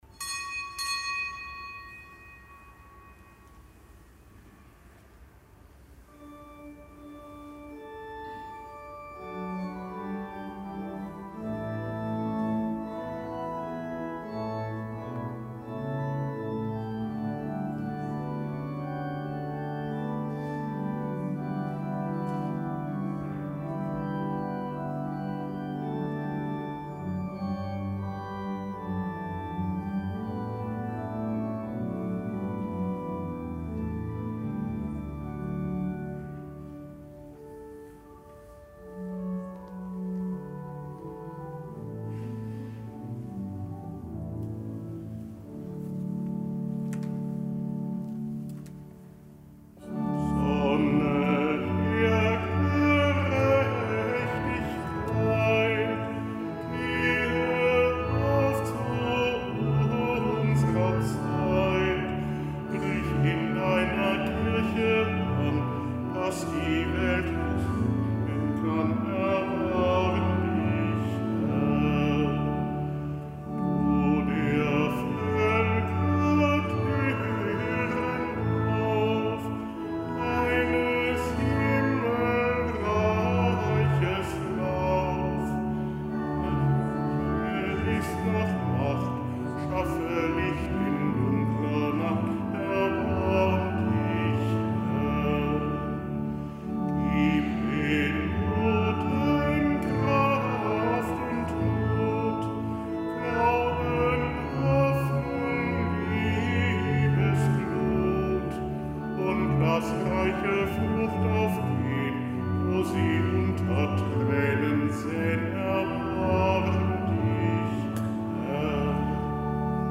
Kapitelsmesse am Dienstag der vierten Woche im Jahreskreis